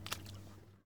blood4.ogg